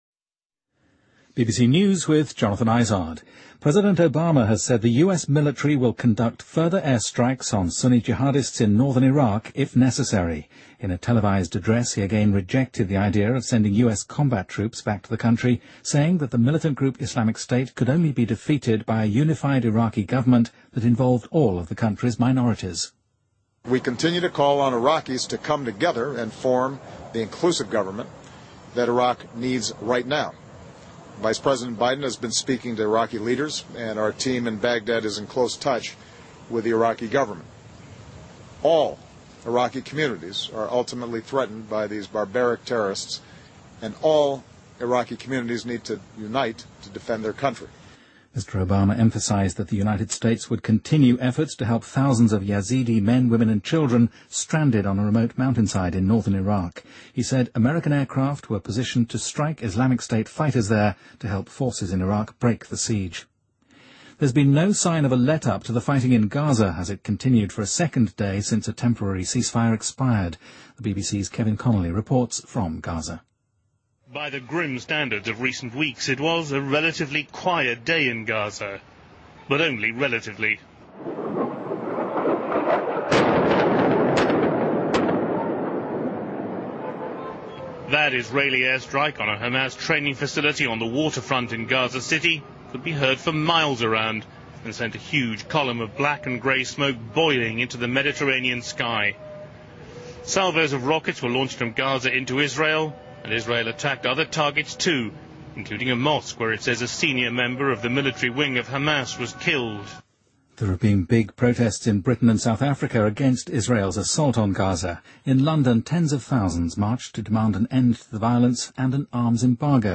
BBC news:一辆载着旅游者的大巴车在西藏坠崖|BBC在线收听